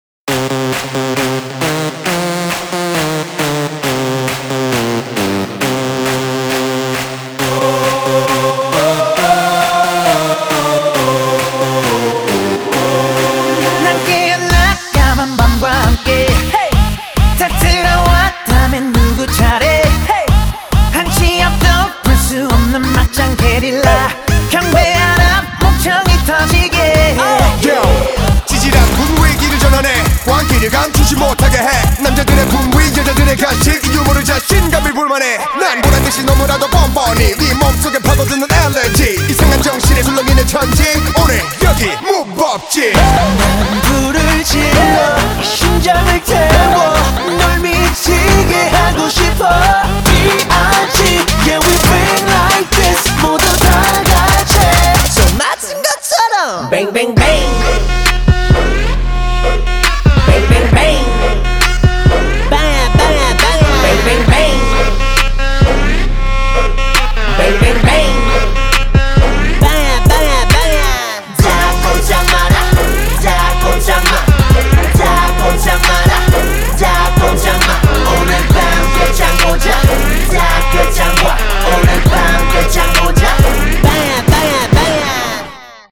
BPM135
Audio QualityPerfect (High Quality)
Genre: K-Pop